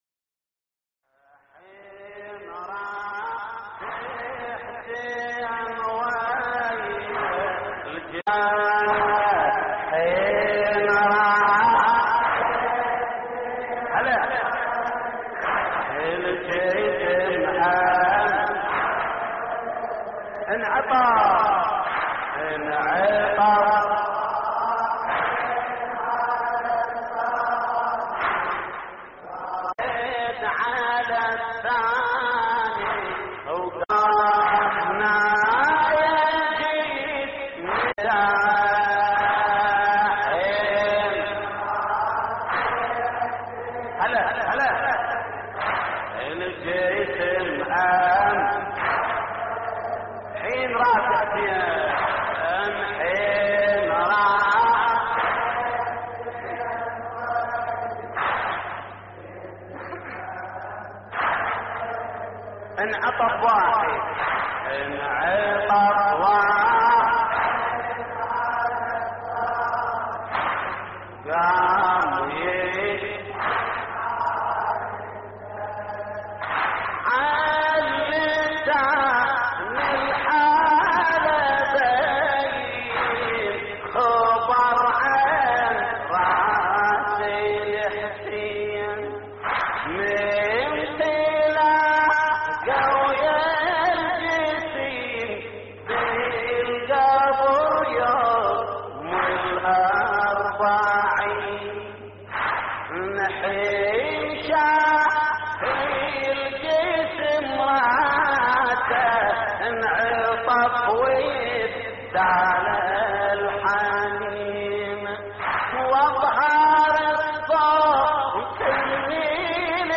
تحميل : حين راس حسين / الرادود جليل الكربلائي / اللطميات الحسينية / موقع يا حسين